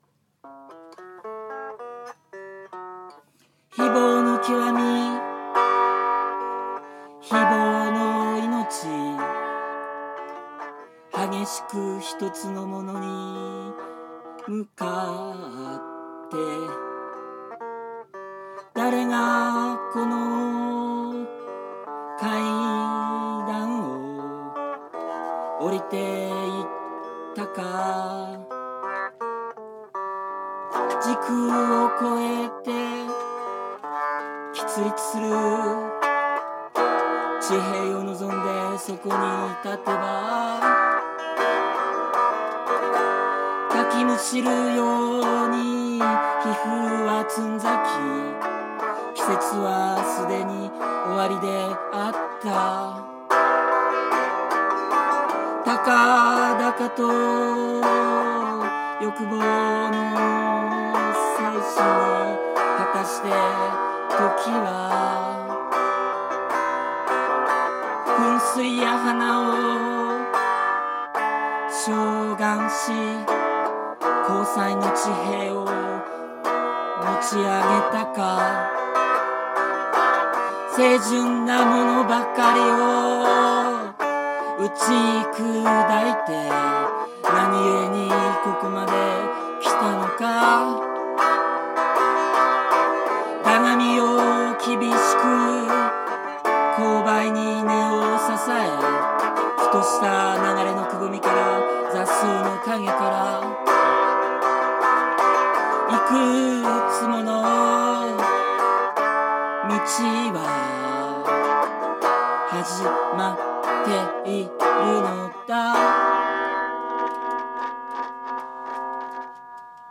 象さんギター1本で様々な詩（下記詩人参照！）にメロディをつけて歌うという好内容！